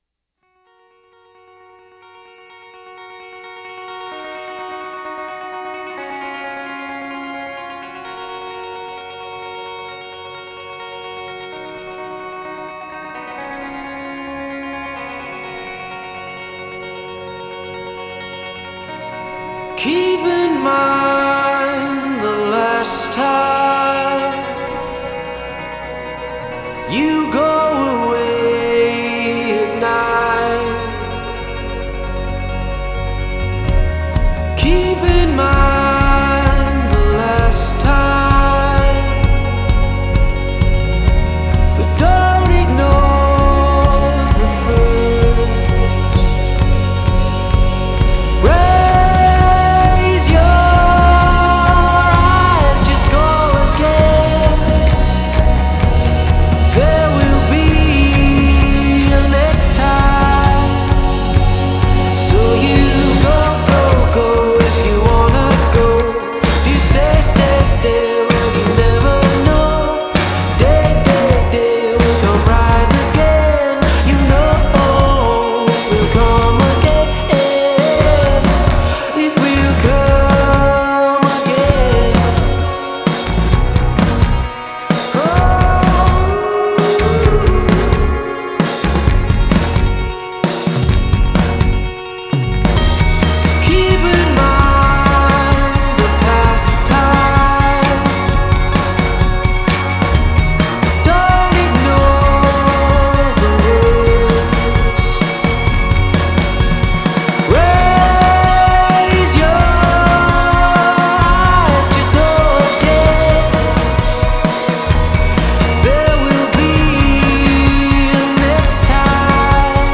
a polished beauty of a ballad